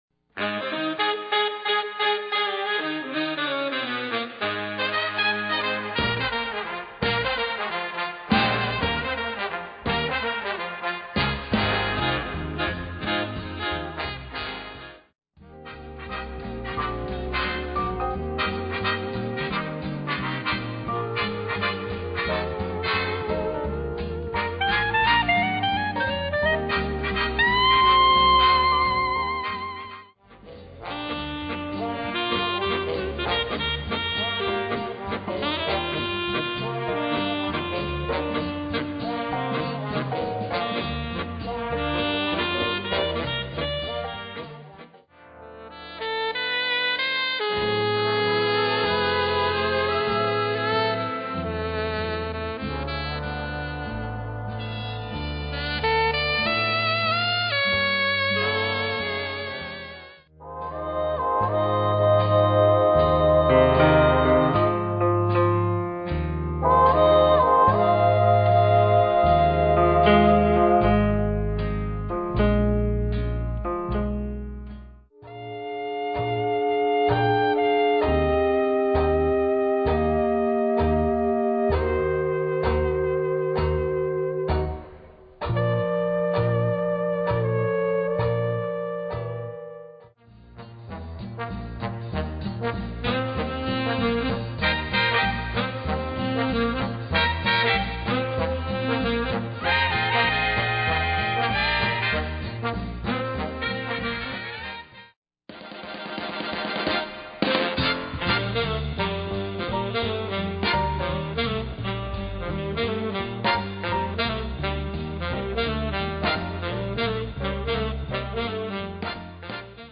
Swing to the music of the 30's, 40's and World War II